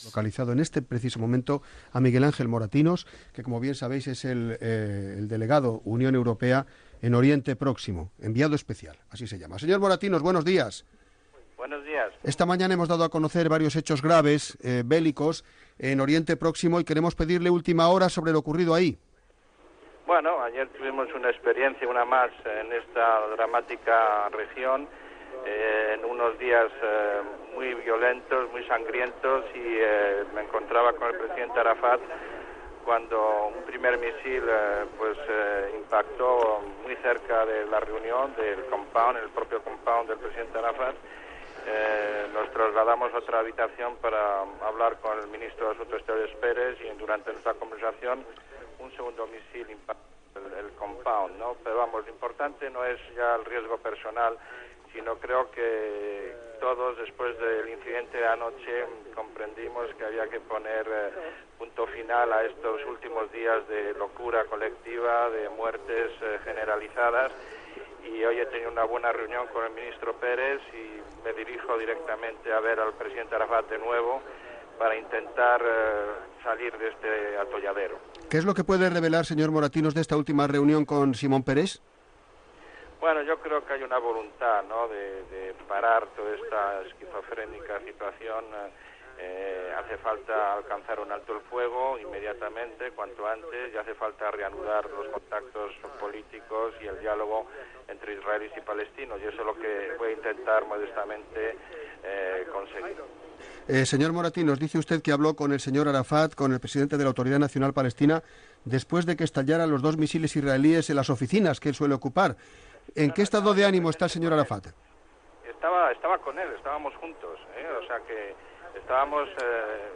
El dia abans, un helicòpter israelià ataca amb míssils la seu a Ramalla d'Iàssir Arafat, president de l'Autoritat Nacional Palestina, que estava sota arrest domiciliari per ordres del govern israelià. Ho explica Miguel Ángel Moratinos, enviat especial de la Unió Europea, que estava amb ell en aquell moment.
Informatiu